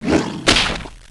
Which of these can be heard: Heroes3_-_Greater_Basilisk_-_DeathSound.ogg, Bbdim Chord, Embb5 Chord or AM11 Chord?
Heroes3_-_Greater_Basilisk_-_DeathSound.ogg